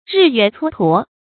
日月蹉跎 注音： ㄖㄧˋ ㄩㄝˋ ㄘㄨㄛ ㄊㄨㄛˊ 讀音讀法： 意思解釋： 蹉跎：時光白白過去。